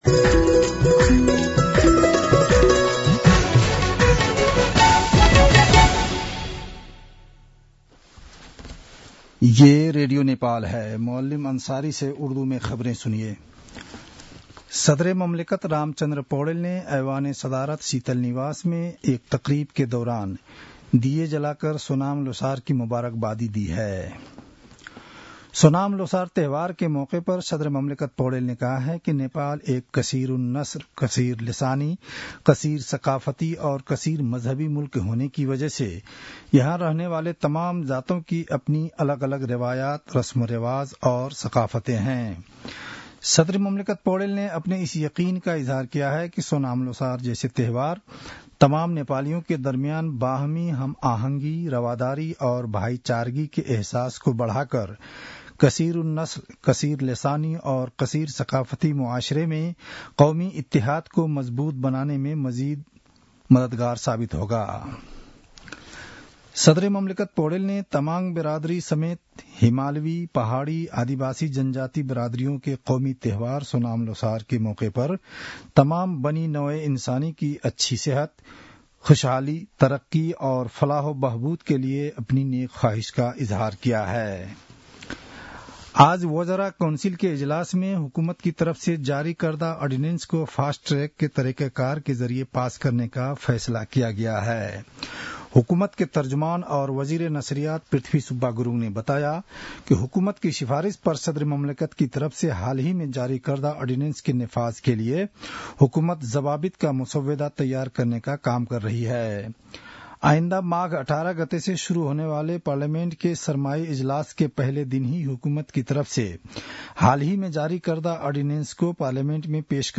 उर्दु भाषामा समाचार : १६ माघ , २०८१